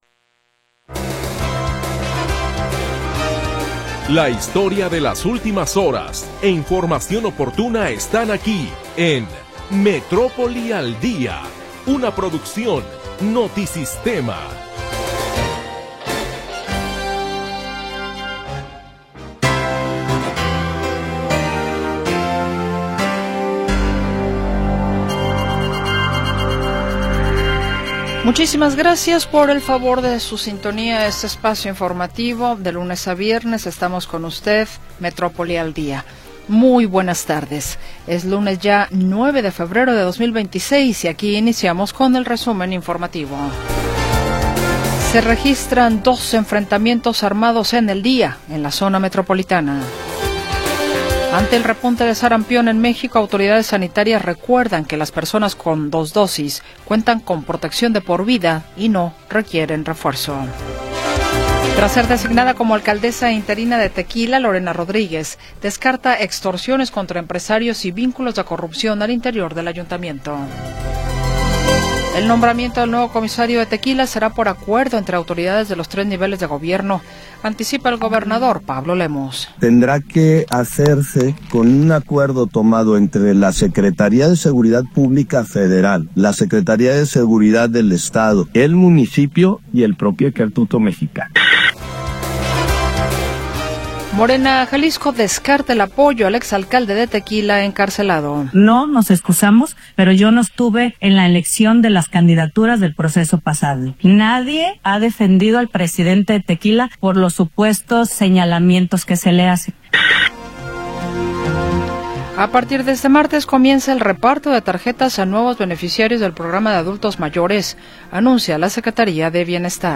Primera hora del programa transmitido el 9 de Febrero de 2026.